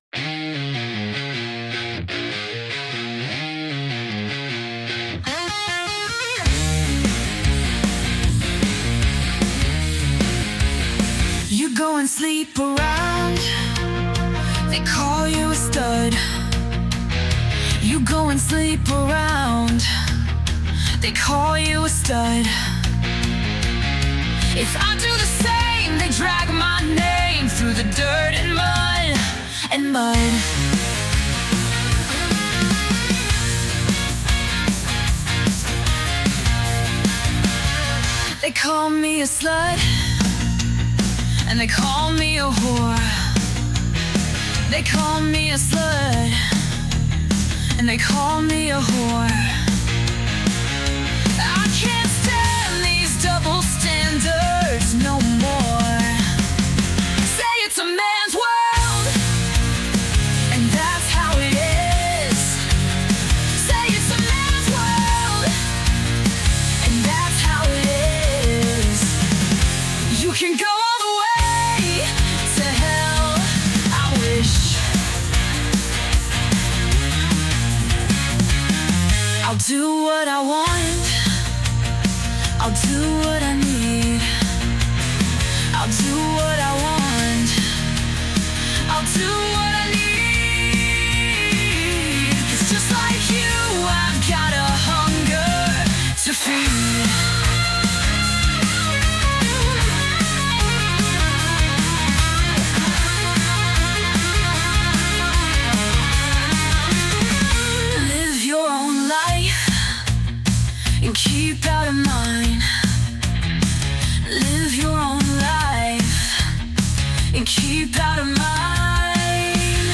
Electric!
That guitar solo was made for a stadium.